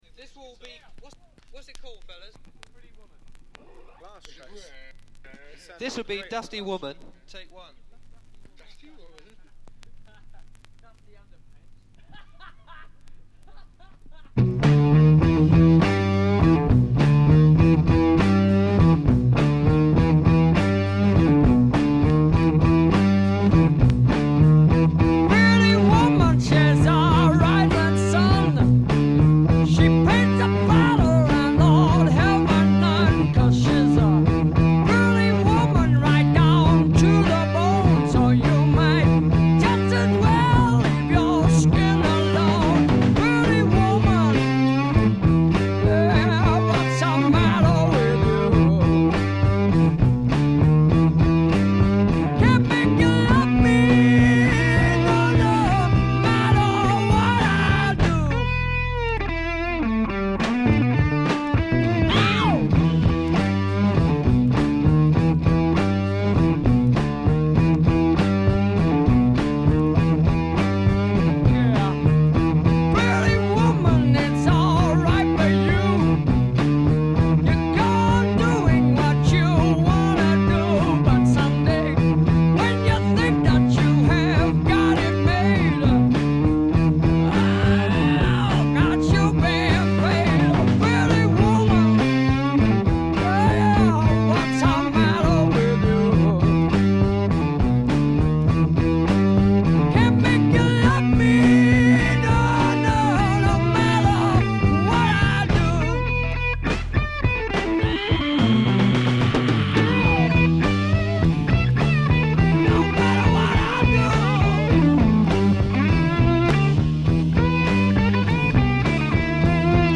By this time the group was just a trio
guitar
bass